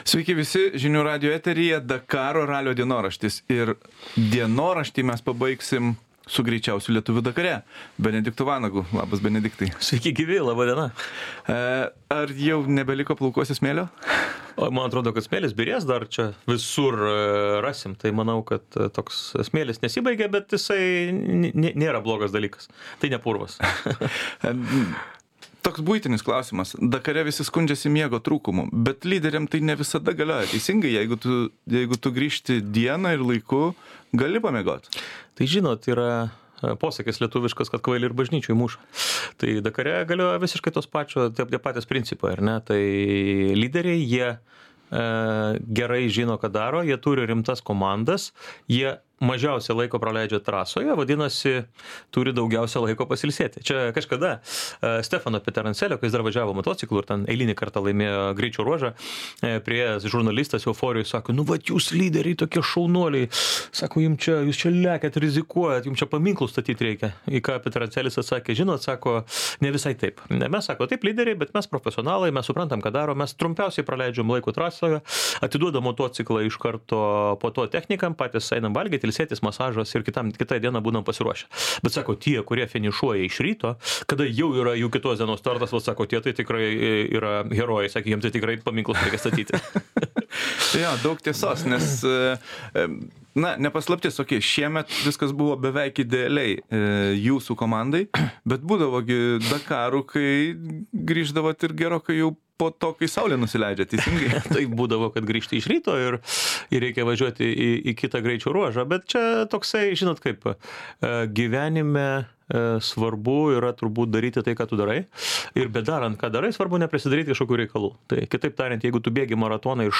Pokalbis su rekordinį rezultatą Dakaro ralyje pasiekusiu lenktynininku Benediktu Vanagu. Saudo Arabijoje B. Vanagas ir estų šturmanas Kuldaras Sikkas bendroje automobilių įskaitoje maratoną baigė 8-oje vietoje.